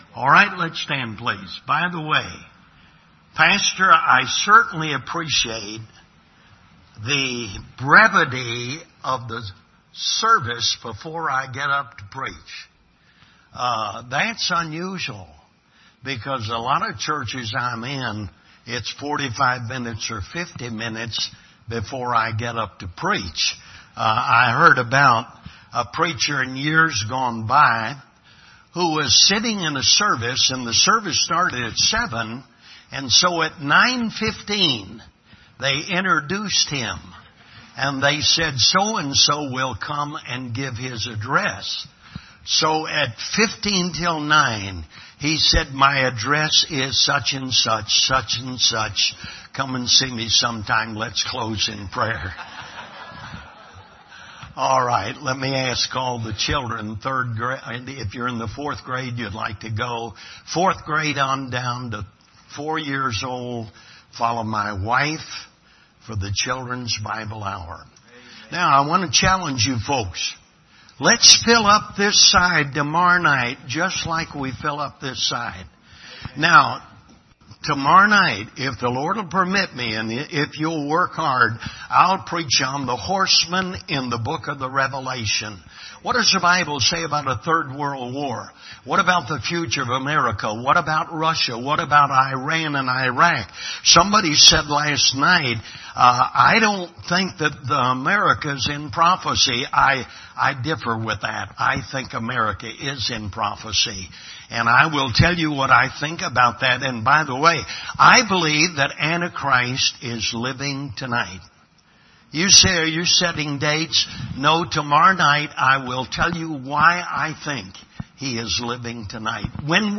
Service Type: Revival Service